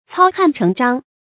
操翰成章 cāo hàn chéng zhāng
操翰成章发音